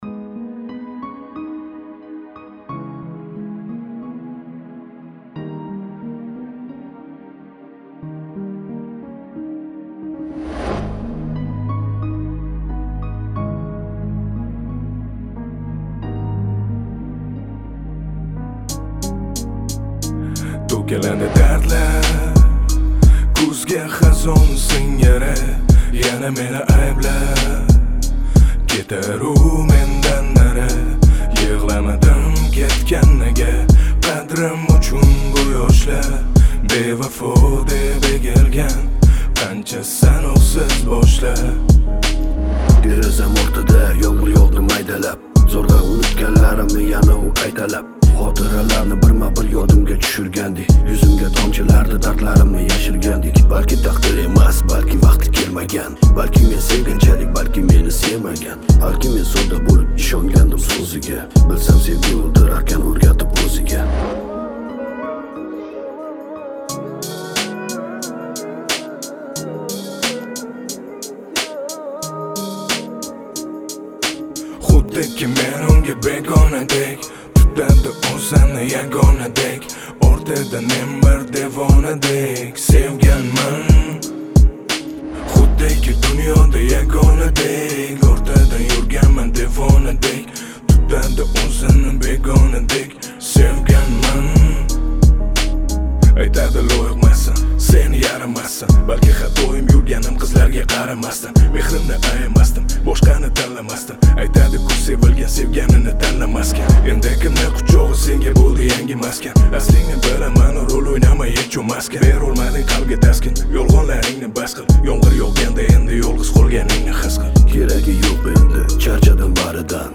Качество: 320 kbps, stereo
Узбекская музыка